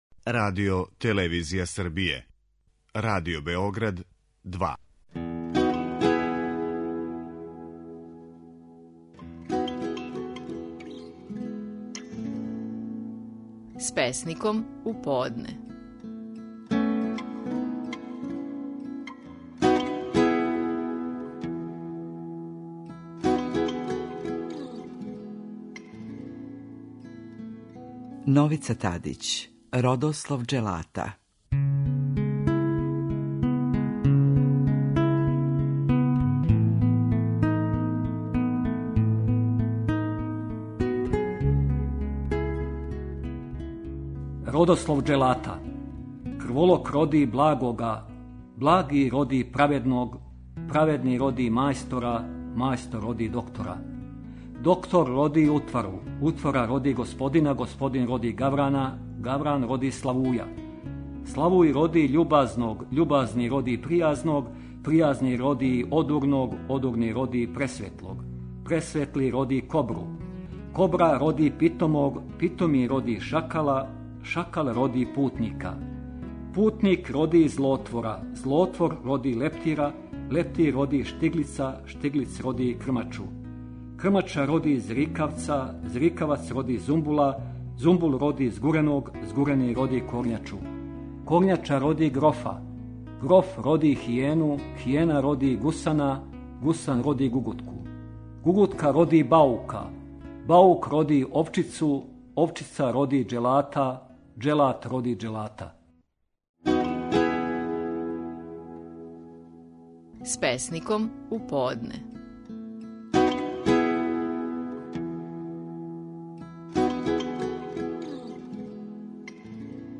Наши најпознатији песници говоре своје стихове
Песник Новица Тадић говорио је своју песму Родослов џелата.